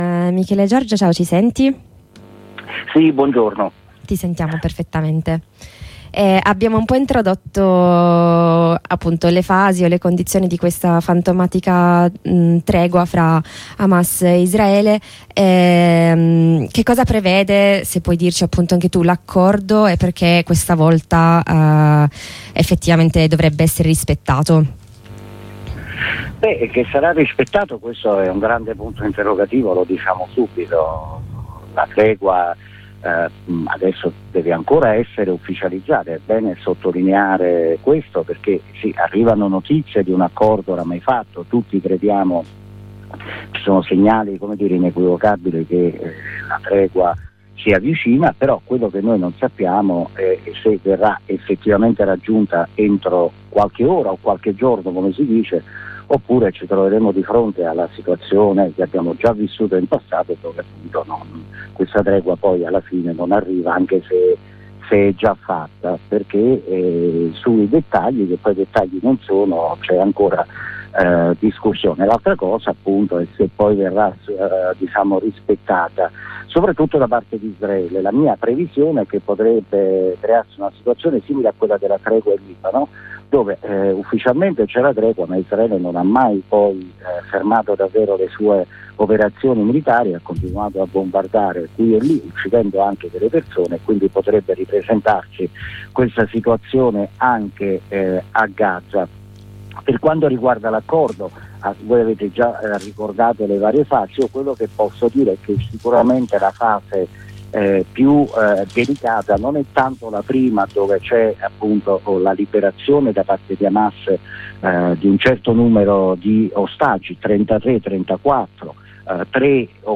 Abbiamo provato a fare il punto della situazione al telefono con